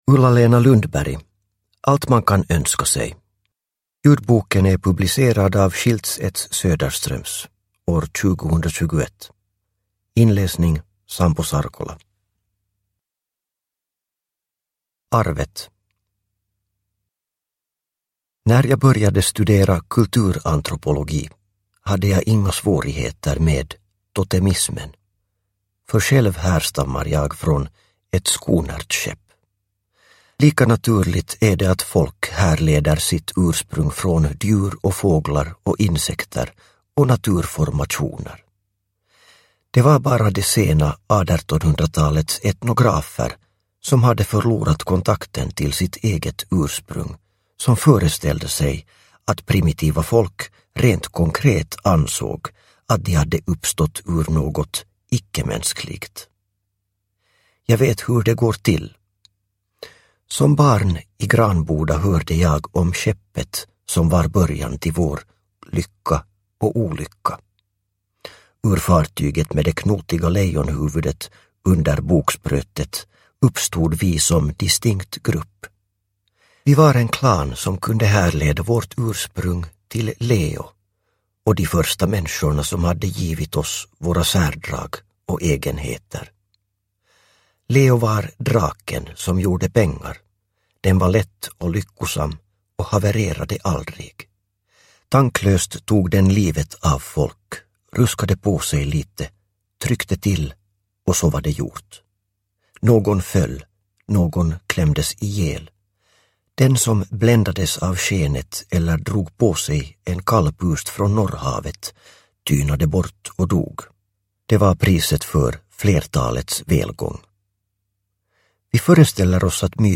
Allt man kan önska sig – Ljudbok – Laddas ner
Uppläsare: Sampo Sarkola